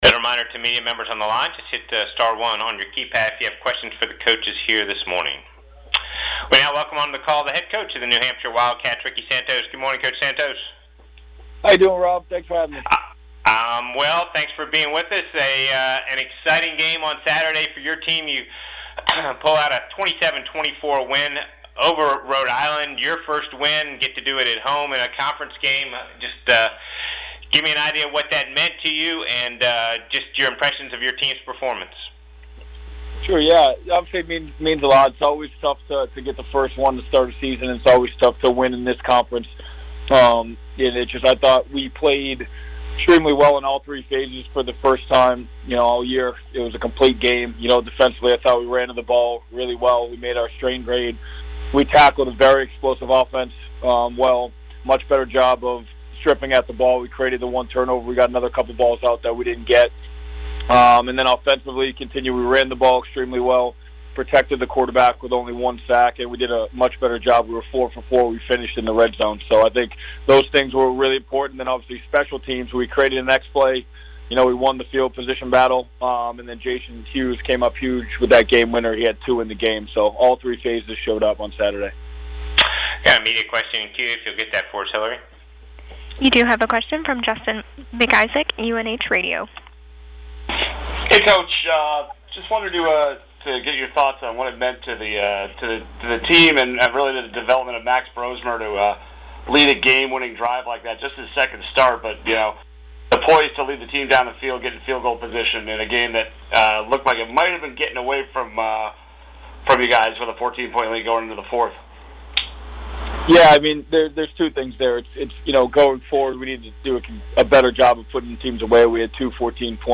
CAA Weekly Teleconference (09.23.19)